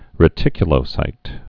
(rĭ-tĭkyə-lō-sīt)